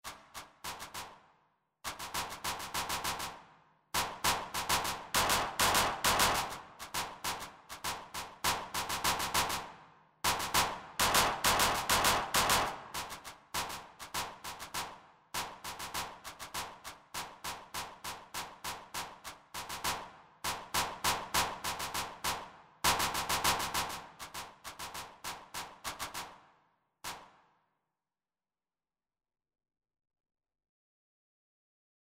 4) Claps & Stamps
This one is counted 3+4.
The final version, the sound is produced only through body-percussion.